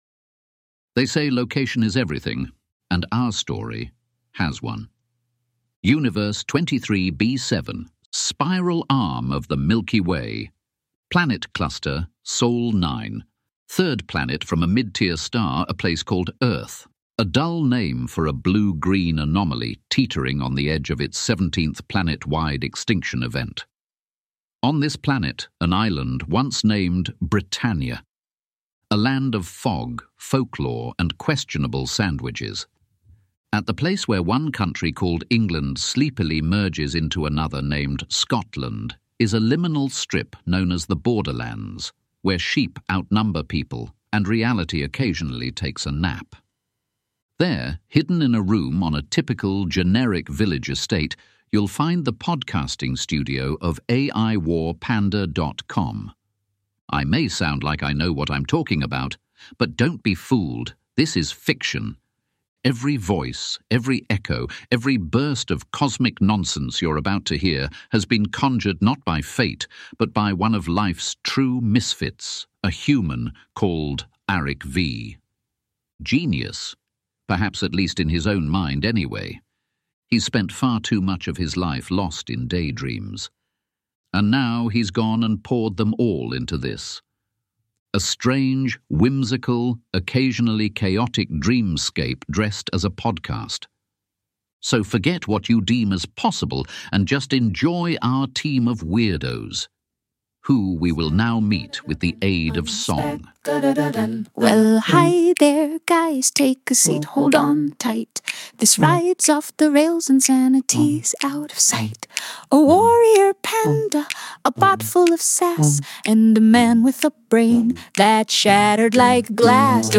Library of Audio Fiction Podcasts
You’ll hear their strange backstories, intergalactic adverts, glitchy interludes, and at least one angry farmer.